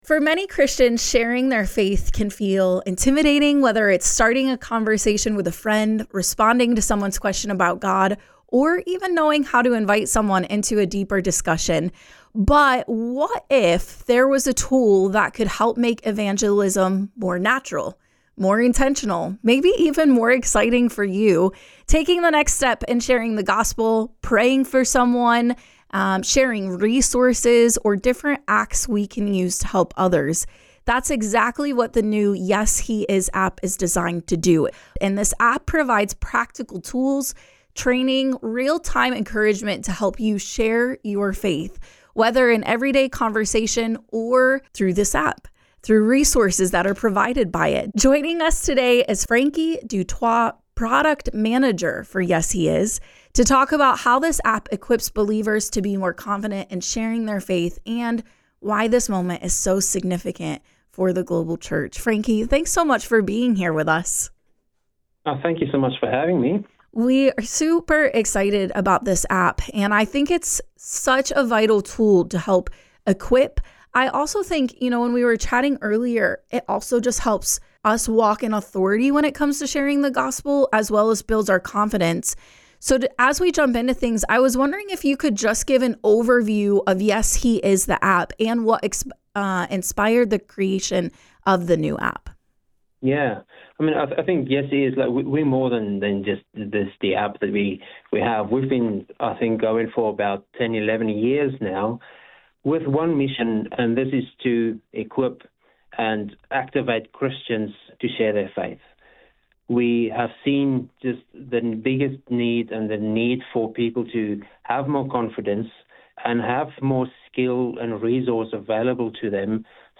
YesHEis-App-Interview-EDITED.mp3